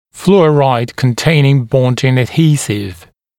[‘fluəraɪd-kən’teɪnɪŋ ‘bɔndɪŋ əd’hiːsɪv] [‘flɔːraɪd][‘флуэрайд -кэн’тэйнин ‘бондин эд’хи:сив] [‘фло:райд]фторосодержащий адгезив